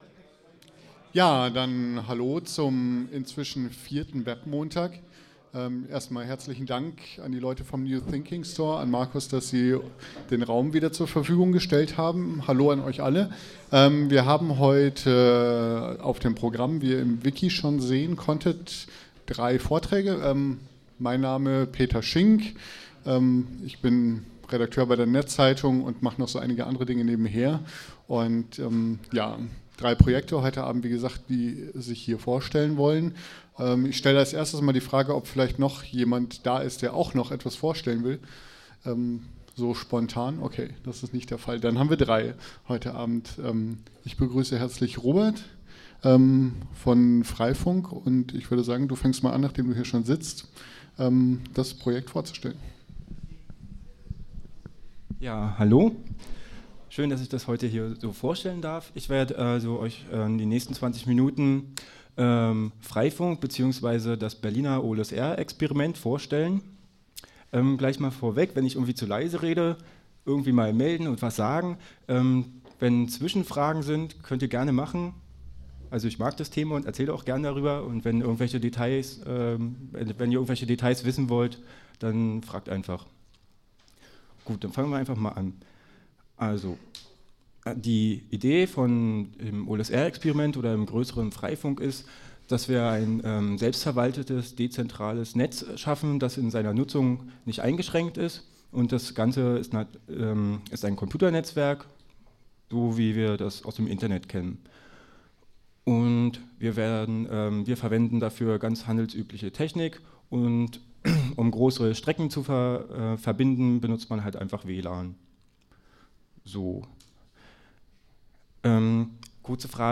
Mitschnitte des 4. Berliner Webmontag
Vom 4. Berliner Webmontag sind jetzt die Mitschnitte fertig, die diesmal Tim Pritlove gemacht hat.